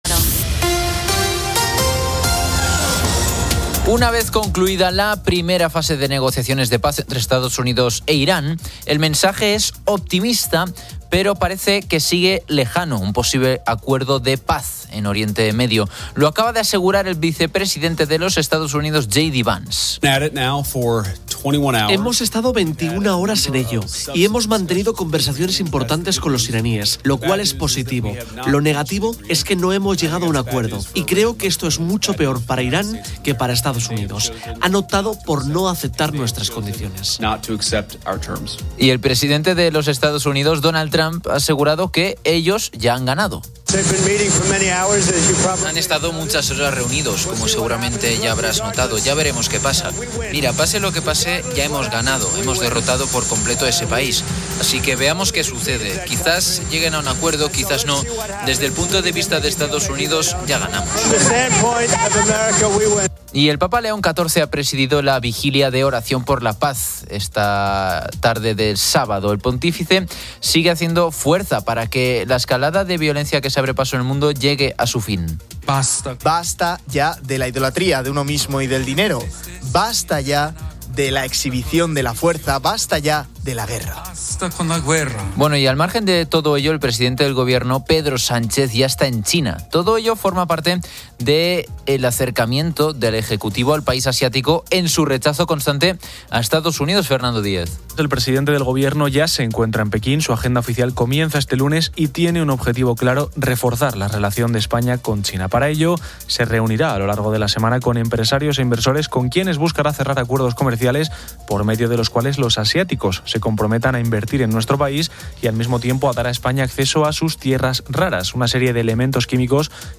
El Grupo Risa presenta a Javier Cárdenas, quien reflexiona sobre la relación de Aitana Sánchez Gijón y Maxi Iglesias, criticando el juicio social a la diferencia de edad, y se escuchan divertidos cortes sobre errores de comentaristas deportivos y una peculiar llamada sobre un curso universitario de "historia de la mierda".